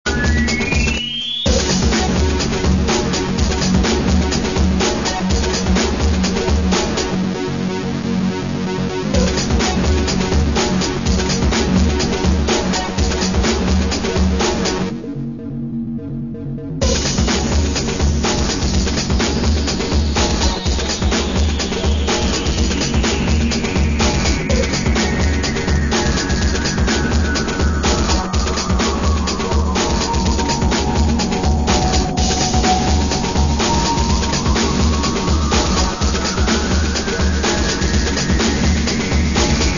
Segunda maqueta con tonos electro-rock bailables.